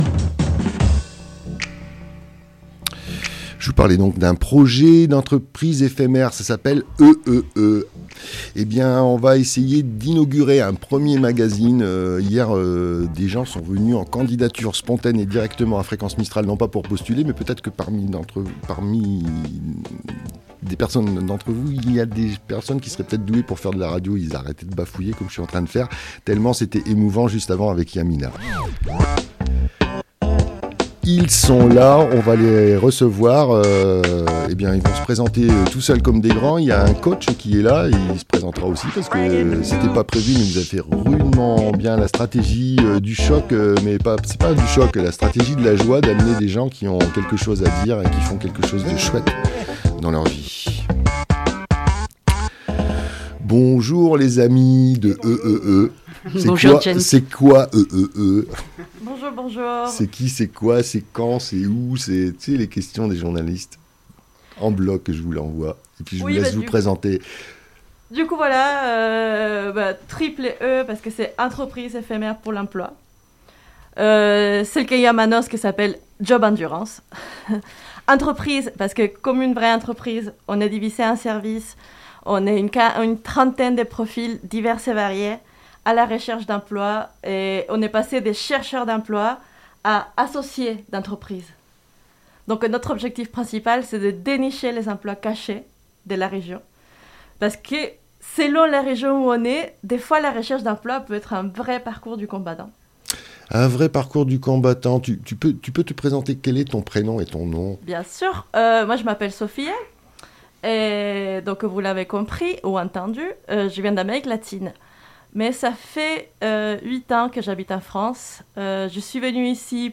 L'une est coach dans cette initiative financée par pôle emploi, et les deux autres sont candidates au succès de leur projet.